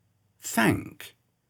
The /θ/ sound: Spellings .